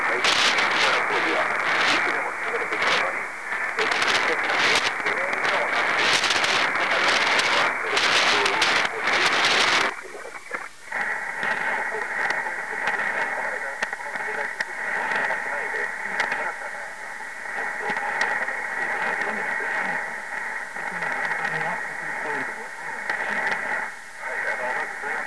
The beginning of the recording is without NR, then I enabled NR about half way through. You can hear the gurgling and watery sound at first, when then gets a little better. The noise is reduced, but I am not sure that I'd really enjoy listening to such audio for any length of time.